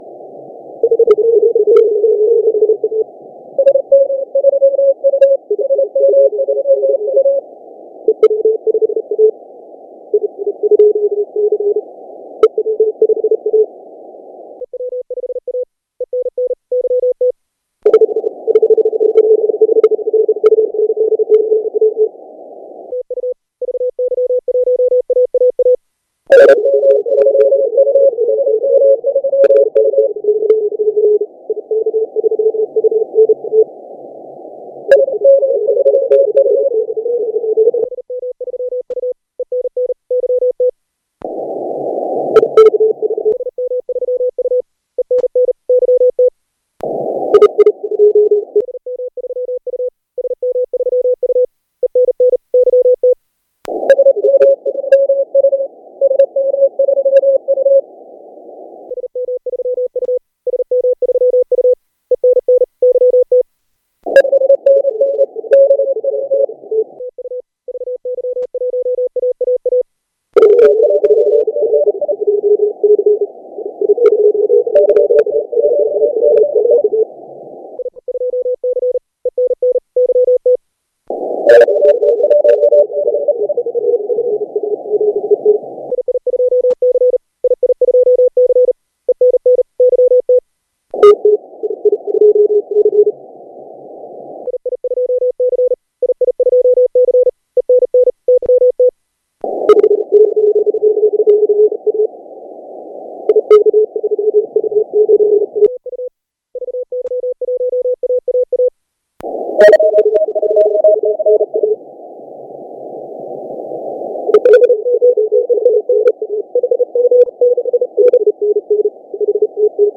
Here's an ARRL DX I did from there with some nice EU sigs: